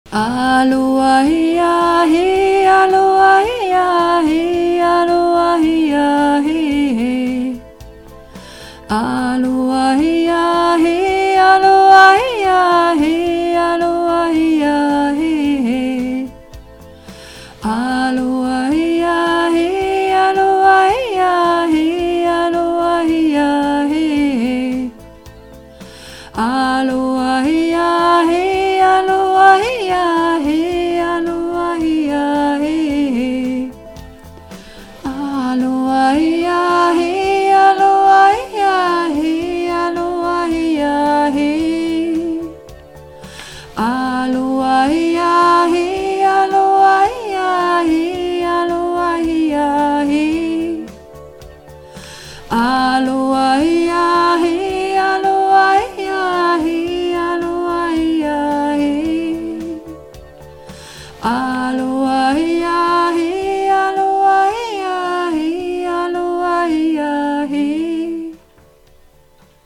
Übungsaufnahmen - Aloha Heja He
Runterladen (Mit rechter Maustaste anklicken, Menübefehl auswählen)   Aloha Heja He (Sopran - Nur "Aloha...")
Aloha_Heja_He__2_Sopran_Nur_Aloha.mp3